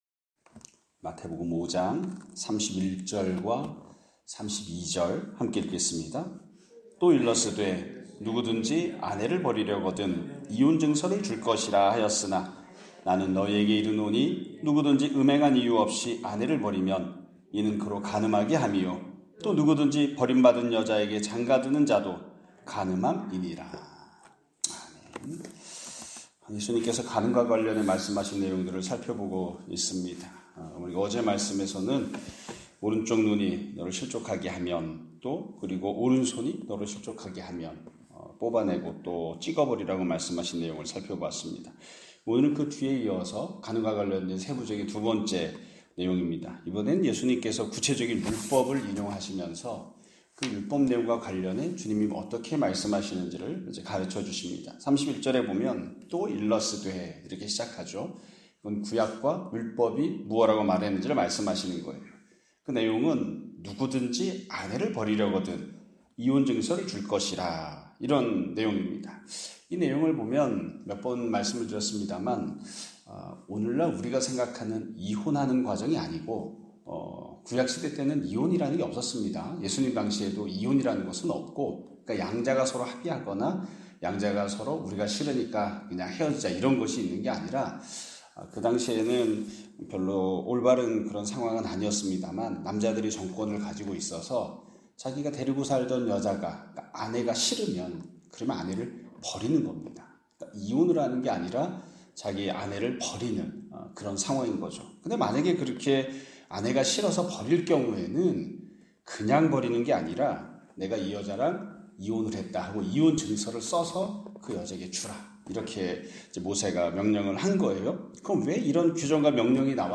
2025년 5월 28일(수요일) <아침예배> 설교입니다.